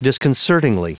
Prononciation du mot : disconcertingly
disconcertingly.wav